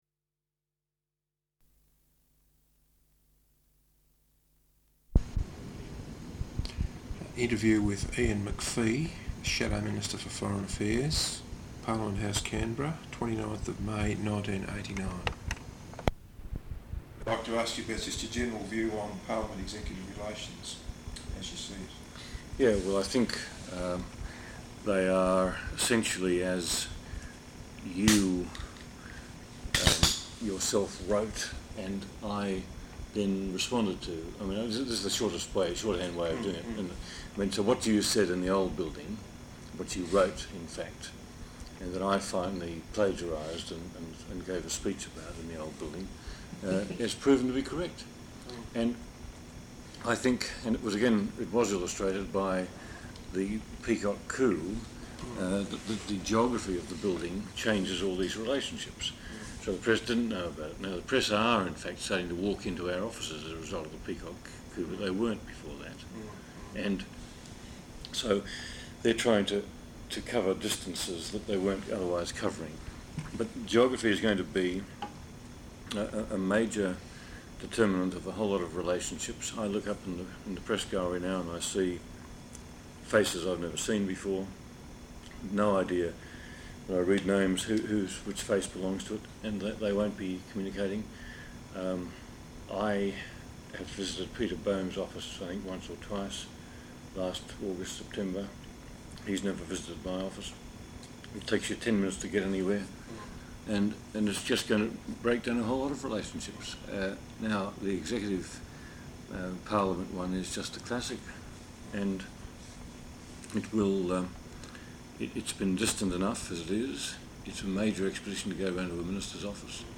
Interview with Ian Macphee, Shadow Minister for Foreign Affairs, Parliament House, Canberra 29th May 1989.